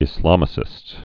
(ĭs-lämĭ-sĭst, ĭz-)